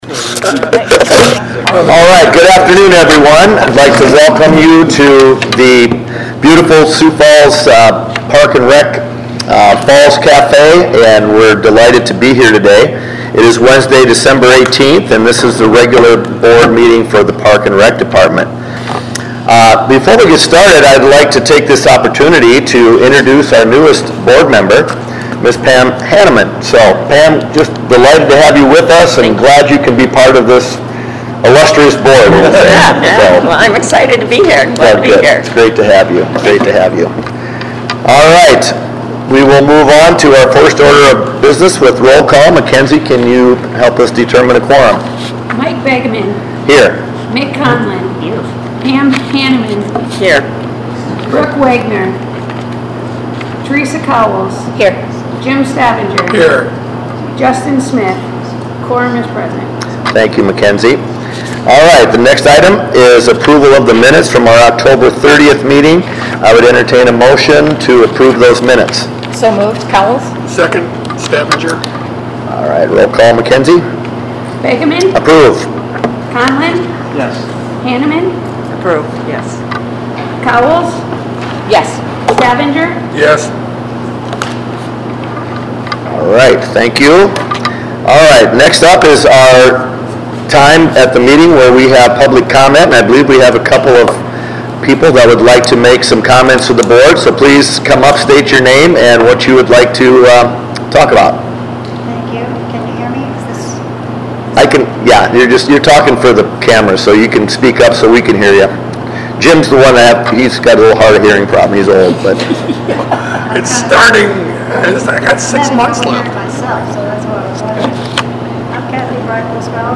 Sioux Falls Park and Recreation Board Meeting